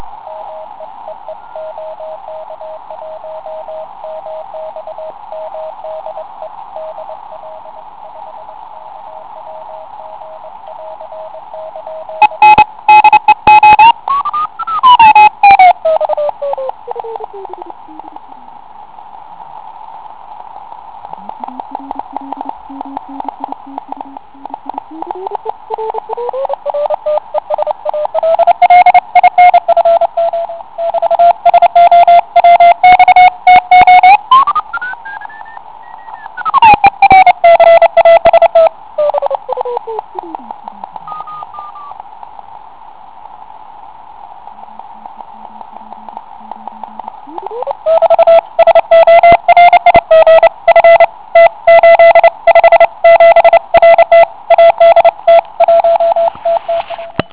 Kliks FT857 (*.wav 250 kB)
Zkrátka značka je příliš zaoblená. Přitom se mi zdá, jakoby při zaklíčování vznikal kmitočtový posun ( FSK) náběhem DDS. Projevuje se to pak "mlaskáním".